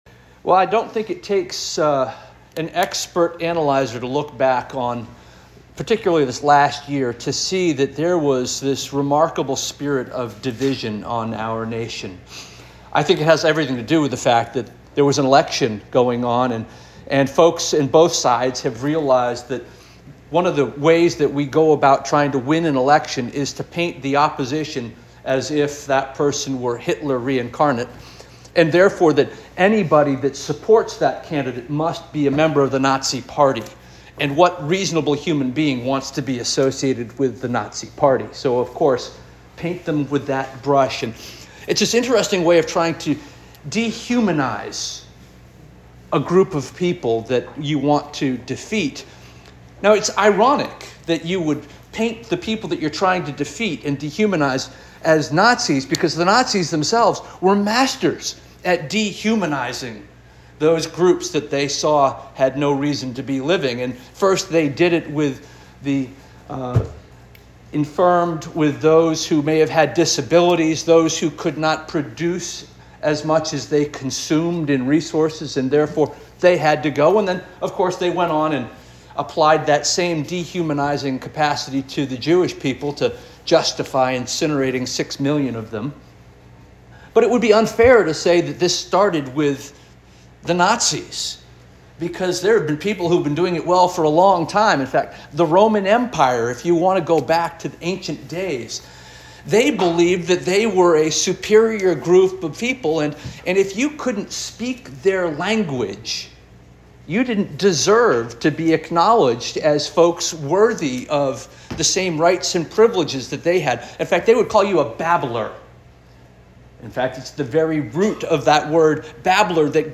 January 12 2025 Sermon - First Union African Baptist Church